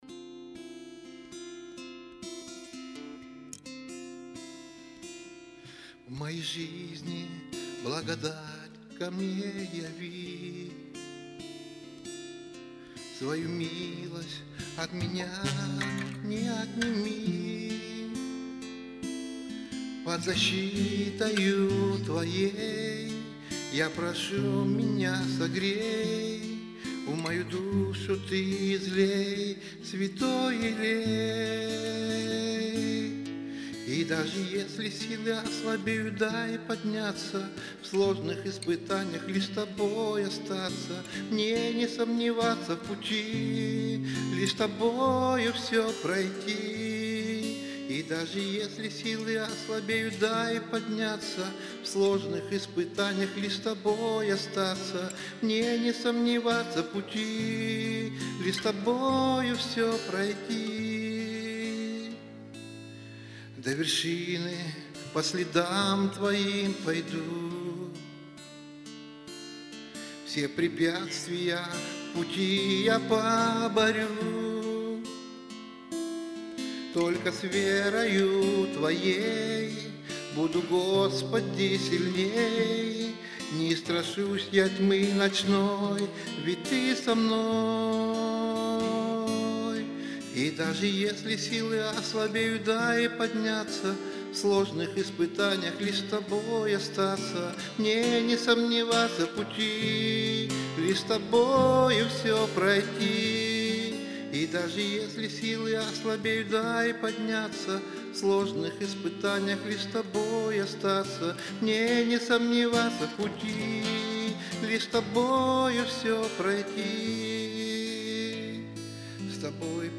Богослужение 29.01.2023
(Пение)